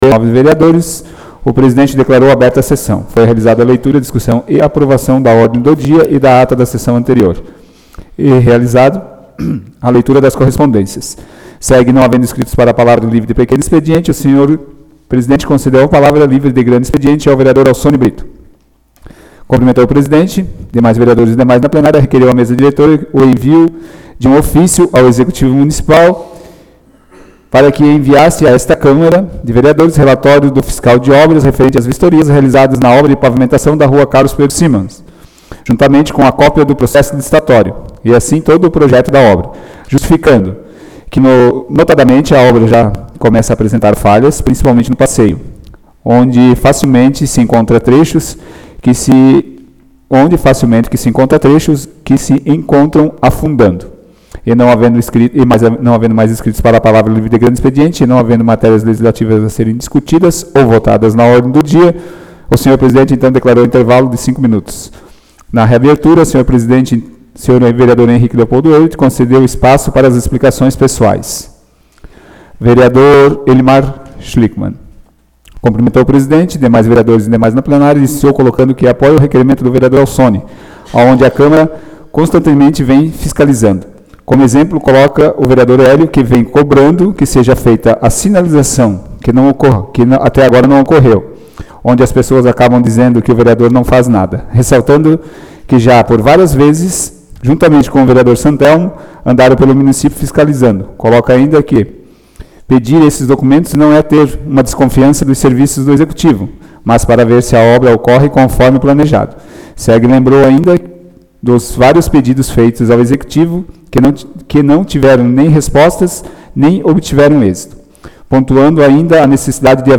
Áudio da 28º Sessão Ordinária do dia 02 de setembro de 2019.